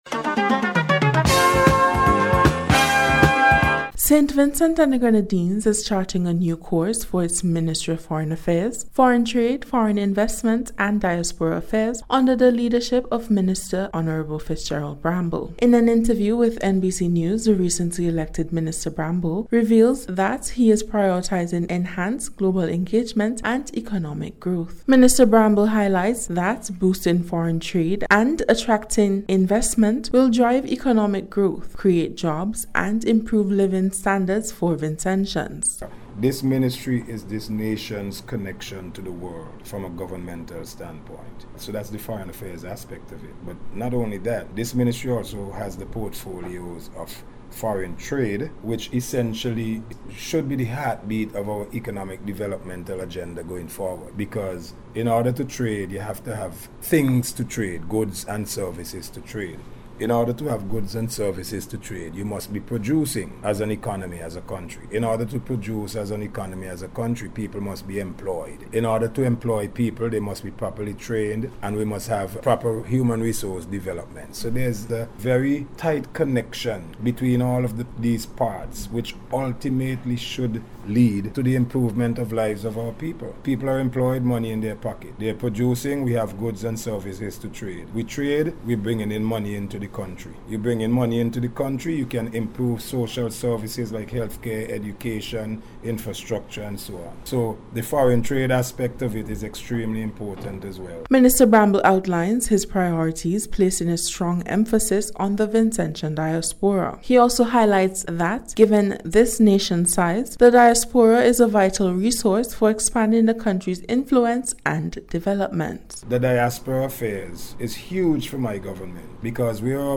NBC’s Special Report- Monday 26th January,2026